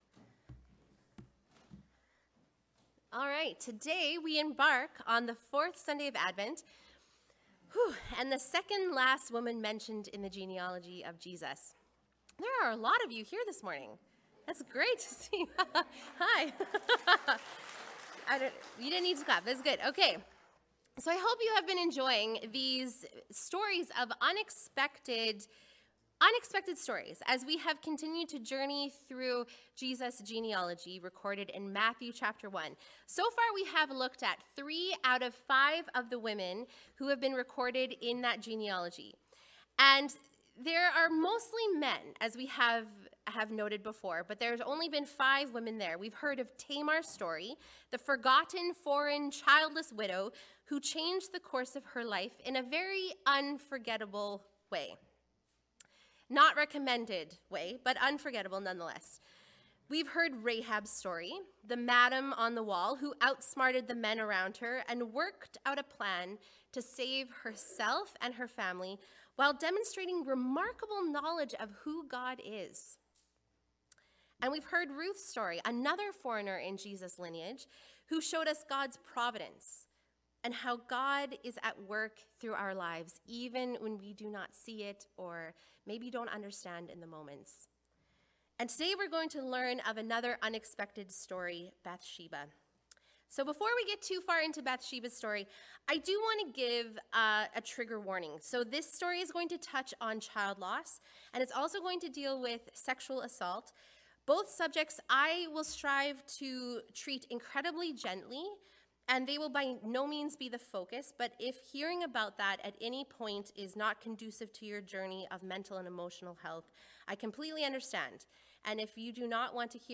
Unexpected: an Advent journey through the surprising stories that lead to the Messiah – Sermon series throughout Advent